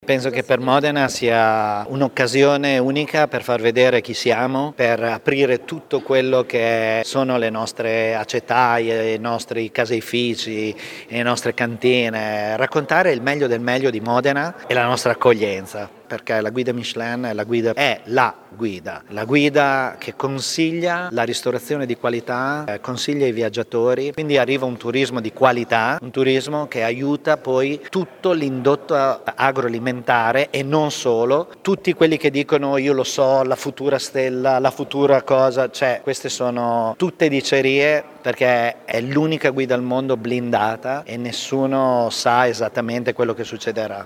Cosa significa ospitare a Modena questo prestigioso evento? Ce lo spiega lo chef stellato Massimo Bottura: